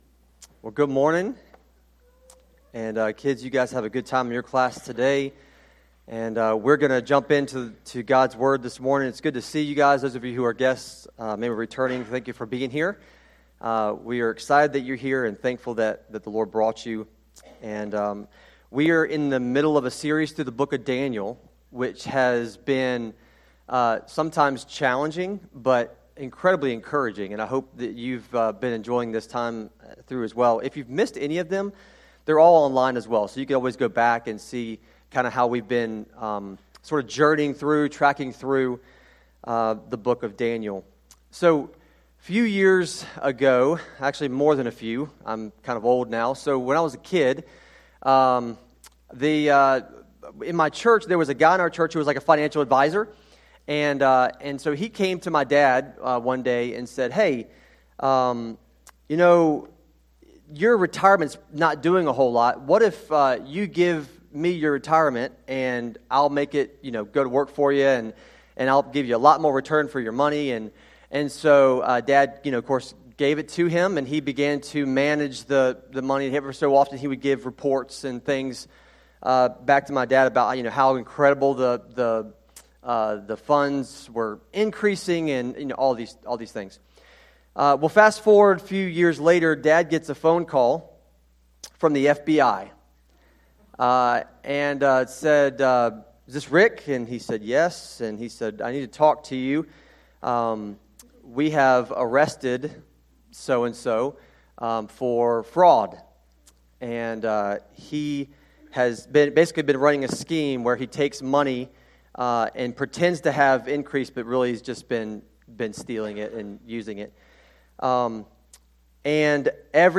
sermon-audio-trimmed-1.mp3